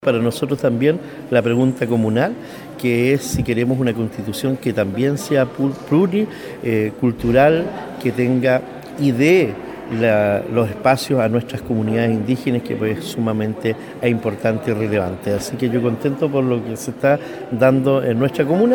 16-ALCALDE-QUELLON-2.mp3